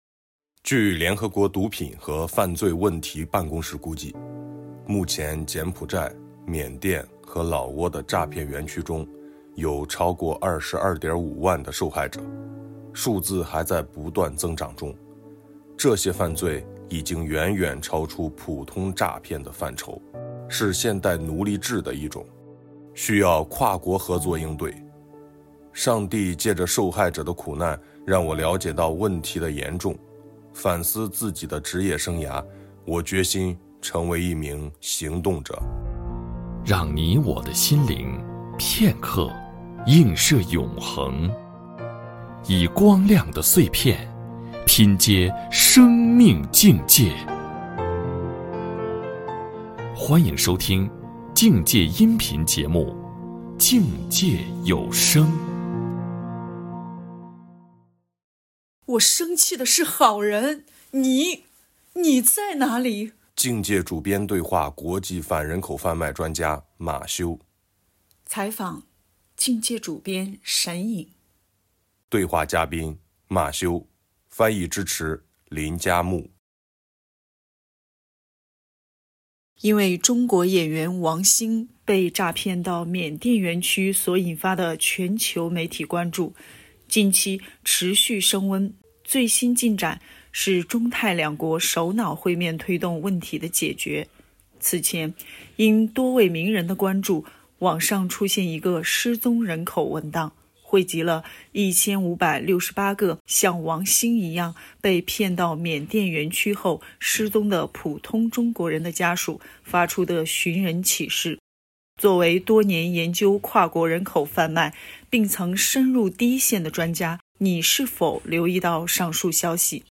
《境界》和iQuest联名专访：香港中文大学校长沈祖尧